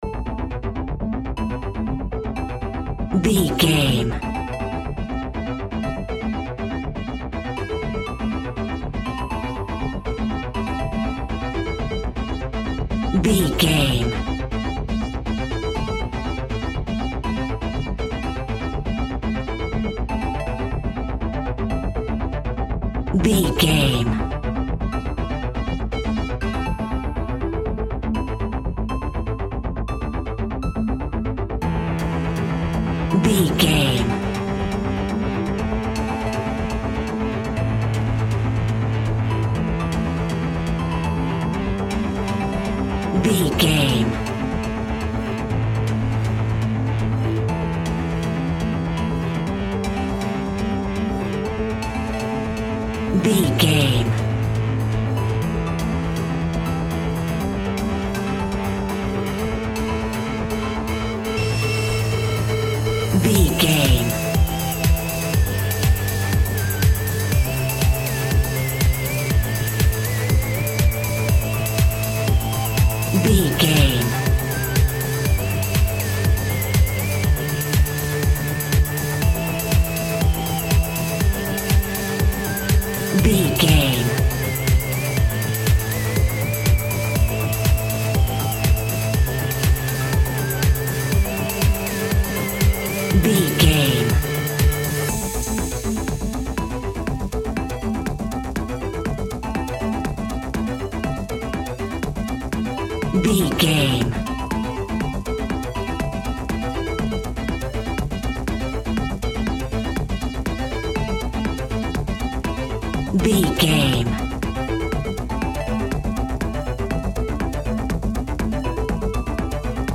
Pop Electronic Dance Music Alt.
Fast paced
Aeolian/Minor
Fast
groovy
uplifting
driving
energetic
bouncy
synthesiser
drum machine
house
techno
trance
instrumentals
synth leads
synth bass
upbeat